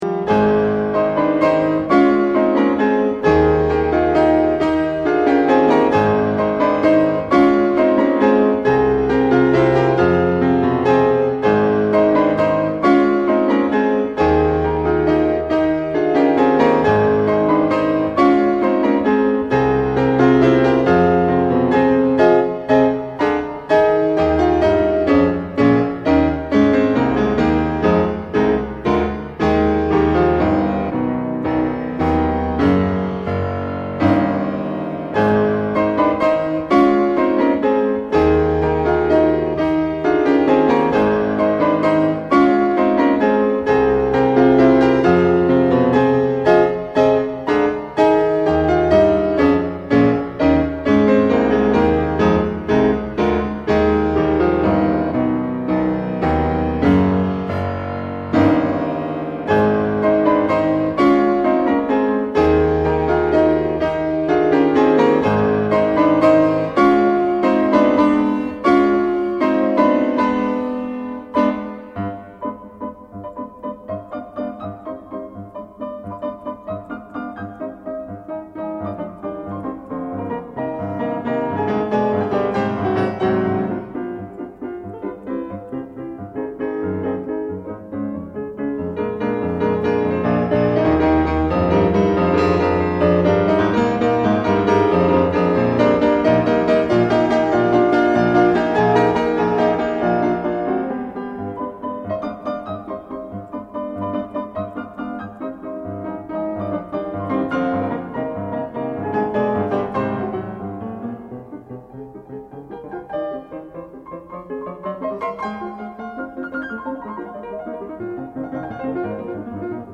Piano
quelques pièces pour le piano